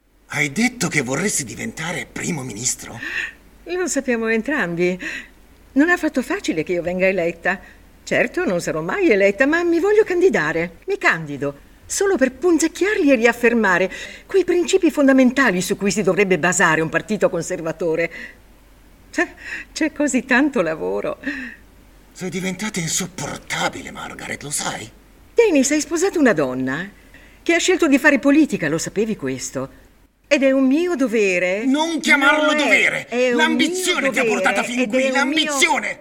Female
40s, 50s, 60s
Authoritative, Confident, Corporate, Friendly, Warm, Assured, Bright, Conversational, Energetic, Reassuring, Soft
Neutral
Microphone: NEUMANN U87, AKG c214
Audio equipment: Focusrite Scarlett solo. Sound treated space in internal room of home.